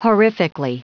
Prononciation du mot : horrifically
horrifically.wav